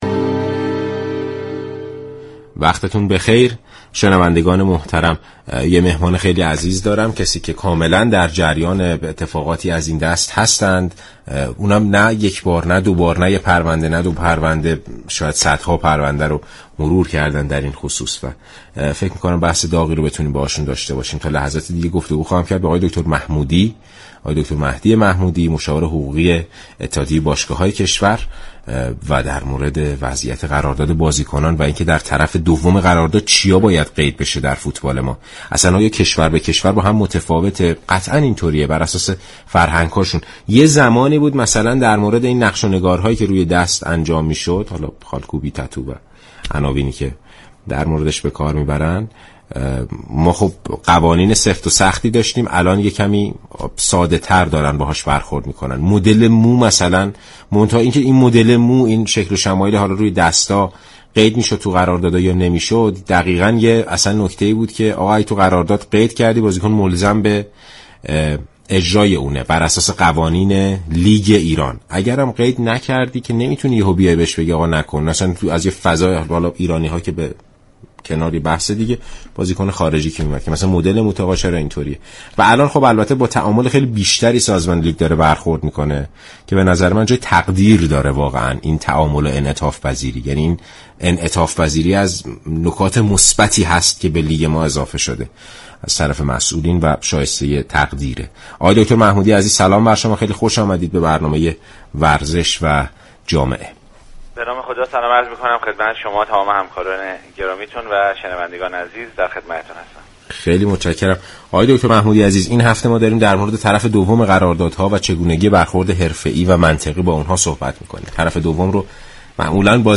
شما مخاطب محترم می توانید از طریق فایل صوتی پیوست شنونده این گفتگو باشید. برنامه ورزش و جامعه همه روزه بجز جمعه ها ساعت 12 به مدت 60 دقیقه از شبكه رادیویی ورزش تقدیم شنوندگان می شود.